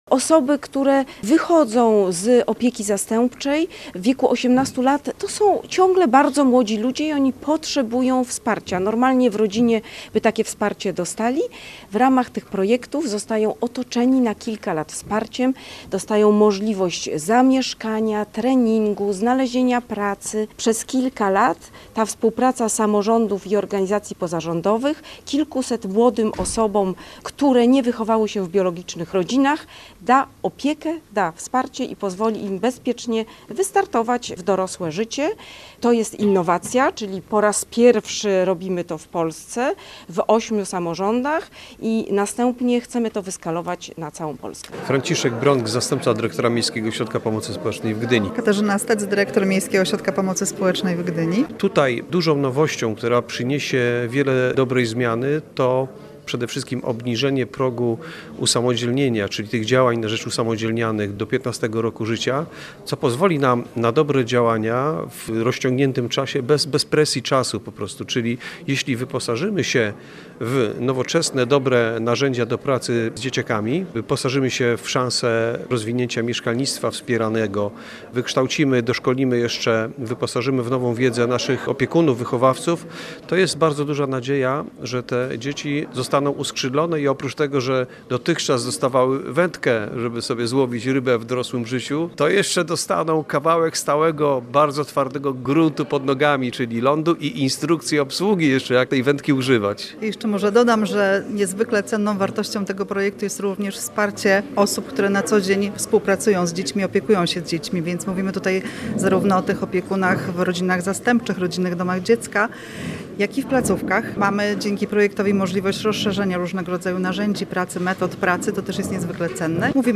Posłuchaj relacji naszego reportera: https